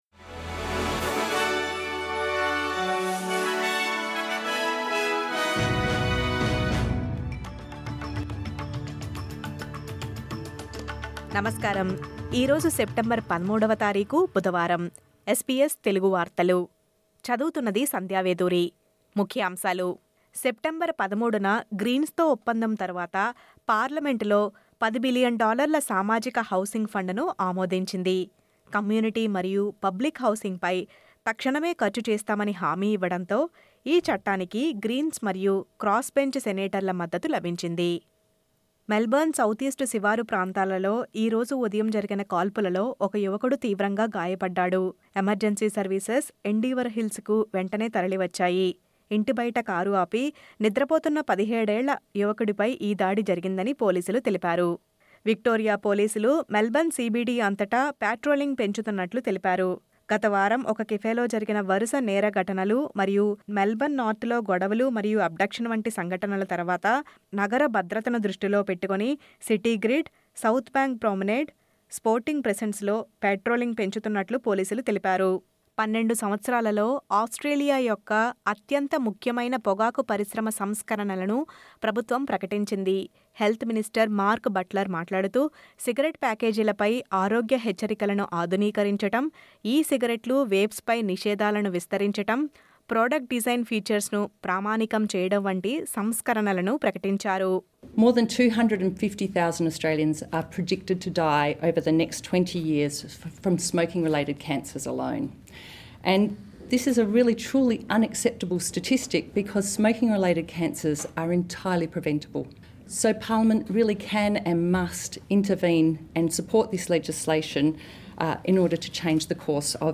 SBS Telugu వార్తలు.